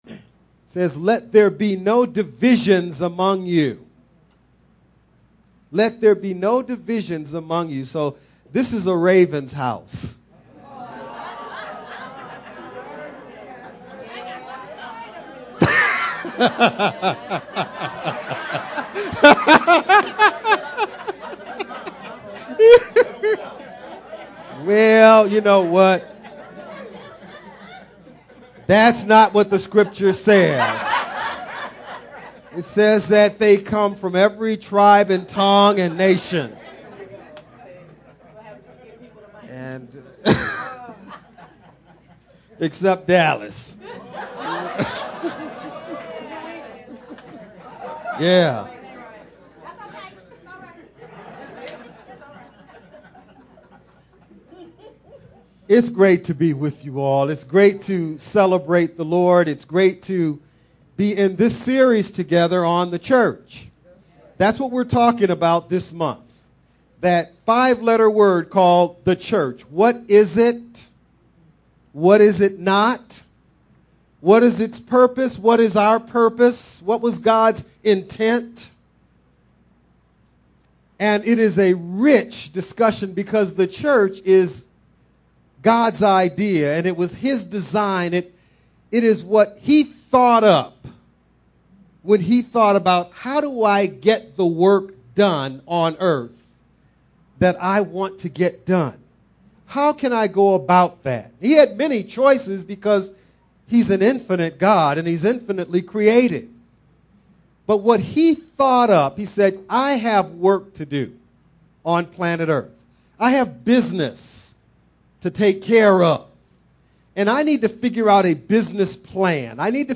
2 Corinthians 2: 14-17 The real culprit for not trusting Christ is the heart, not the Church. Message